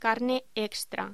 Locución: Carne extra
voz